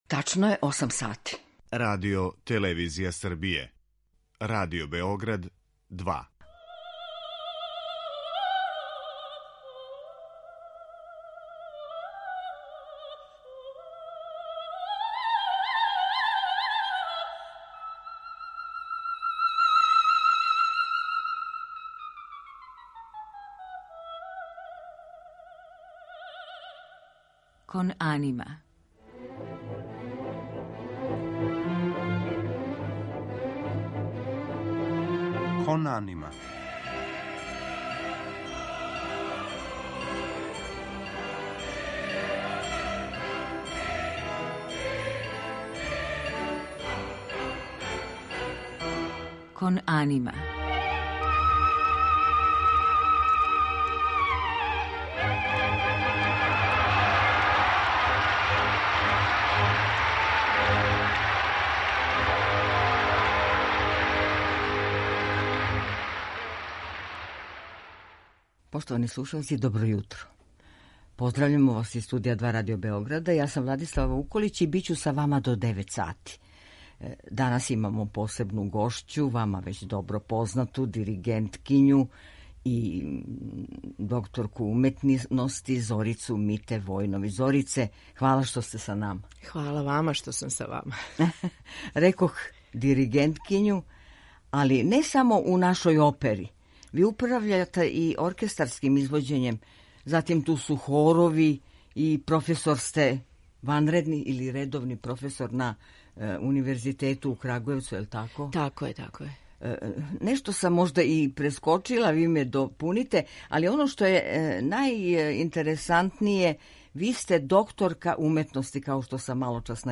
У музичком делу биће емитовани фрагменти из опера